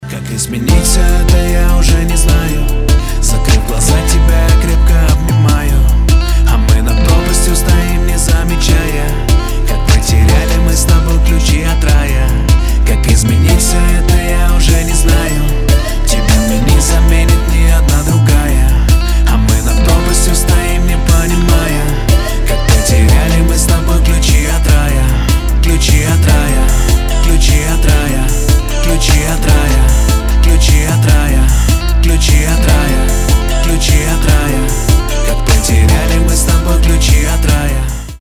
• Качество: 256, Stereo
красивые
Rap